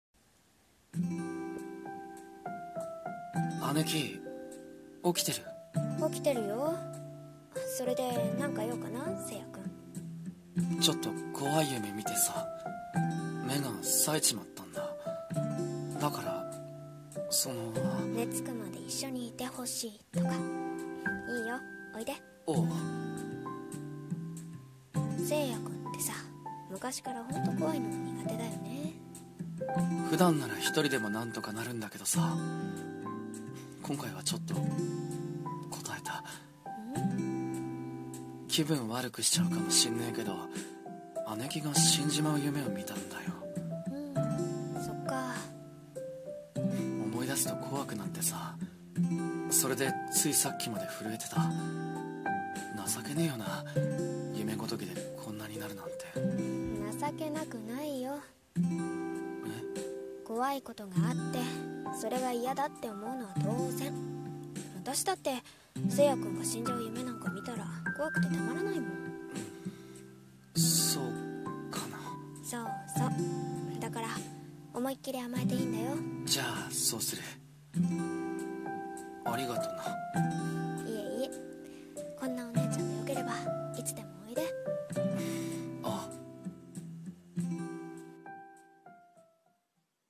声劇台本「ある姉弟の日常」2